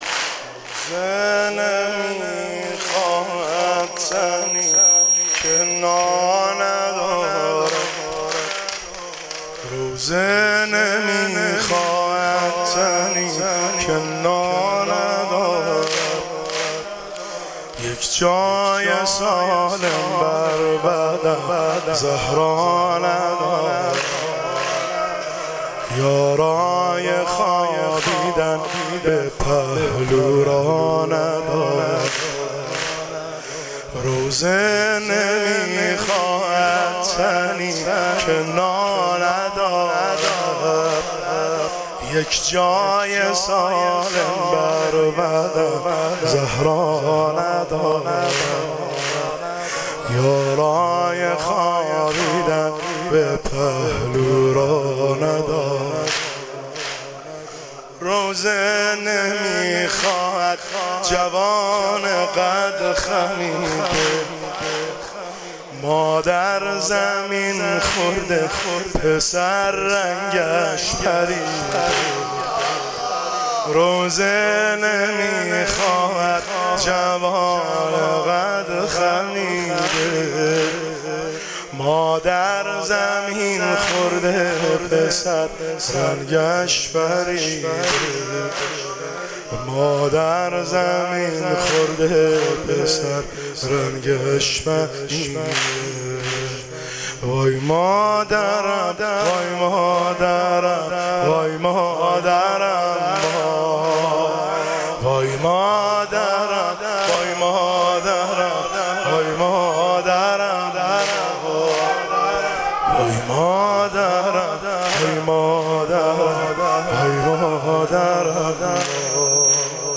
روضه نمیخواهد تنی که نا ندارد سنگین – شب دوم فاطمیه دوم 1404 هیئت بین الحرمین طهران